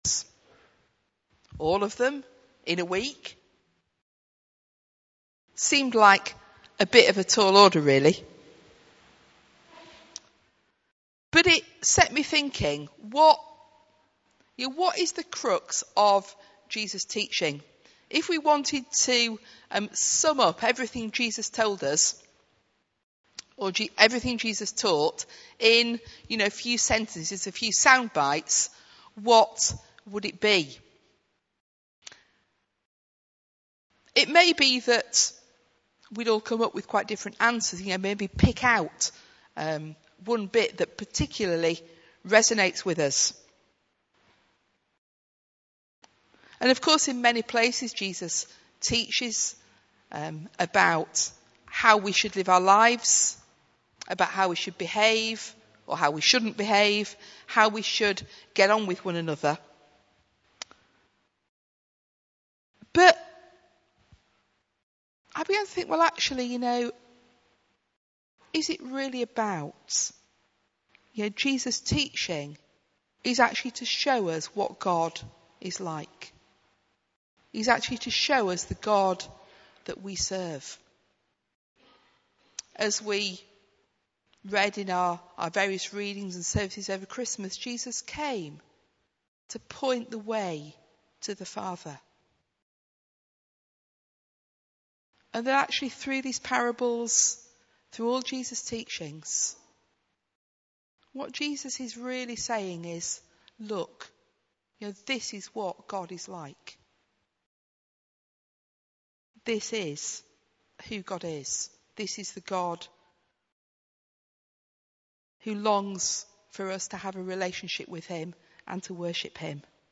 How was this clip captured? in 10:30 Morning Worship, St John's service